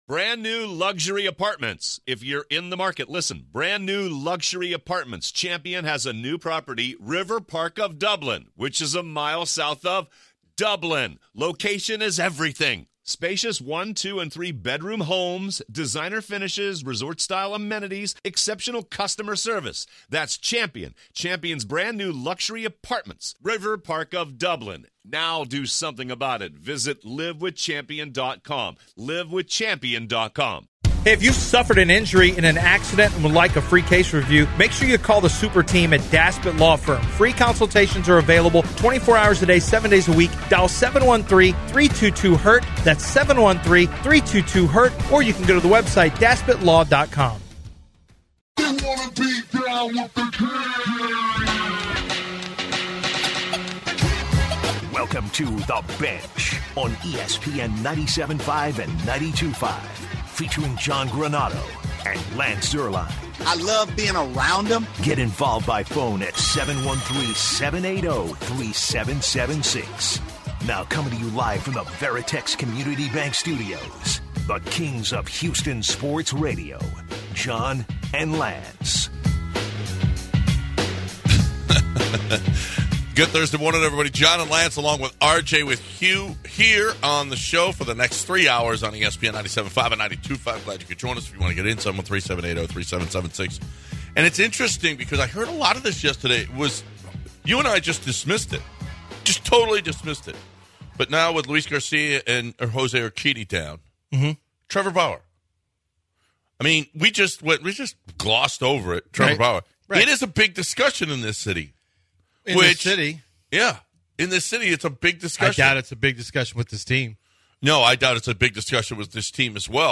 In our opening hour, we hear from Alex Bregman, who discusses the team's offensive struggles and what needs to change. In addition, Jose Abreu hasn't been what many thought he'd be when the Astros signed him...